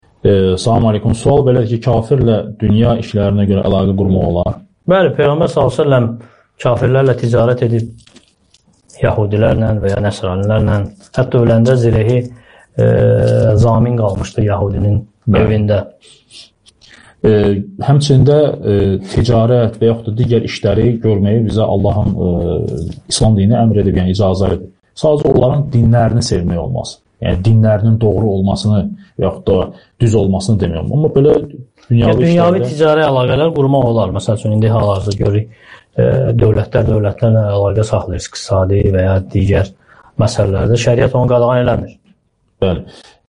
Namaz (SUAL-CAVAB)